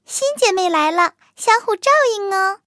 卡尔臼炮建造完成提醒语音.OGG